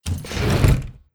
rust-rl/resources/sounds/door/close3.wav at e3a8c545fb977e576db3ea4e4022c8902ab4f3a5
close3.wav